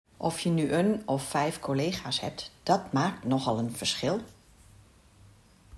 Bij zin A hoor je in het eerste deel van de zin ‘een’, uitgesproken met de stille /e/.
Je zegt daarom [un], ook wel de stomme /e/ of sjwa genoemd.